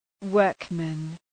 Προφορά
{‘wɜ:rkmən}